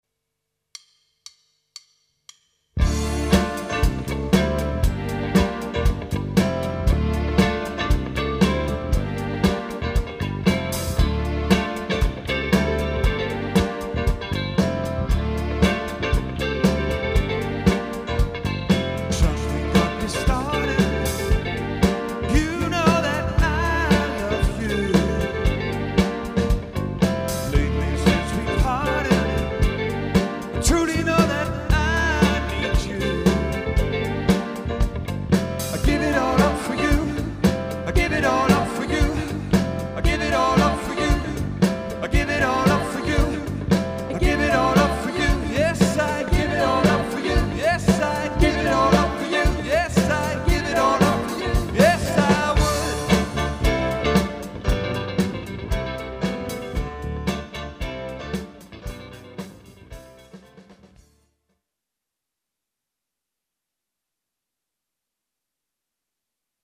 solid cover musik til fester af enhver slags
dansevenlig rock, soul og funk musik
• Coverband
• Rockband